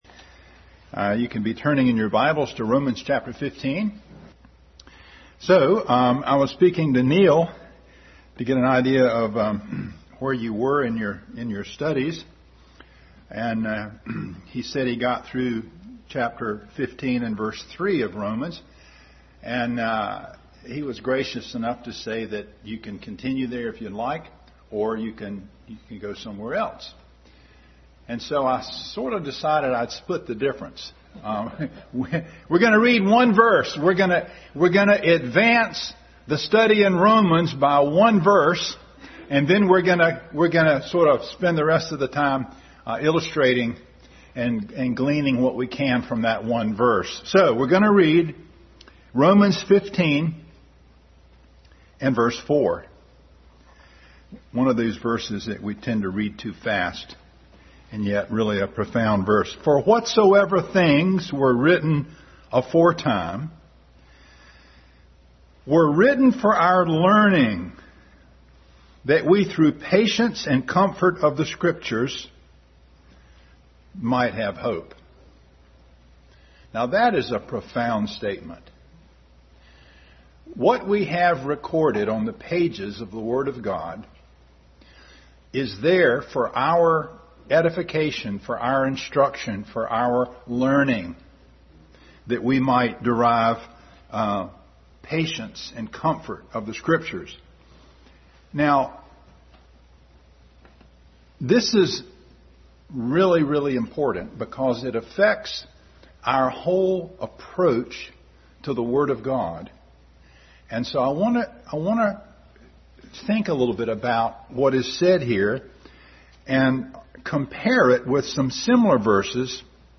Adult Sunday School Class.